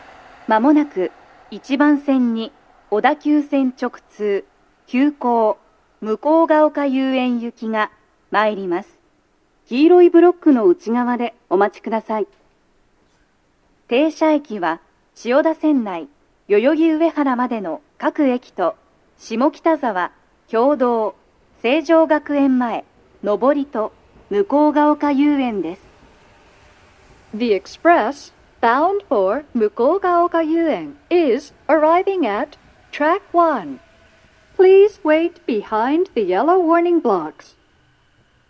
鳴動中に入線してくる場合もあります。
女声
接近放送5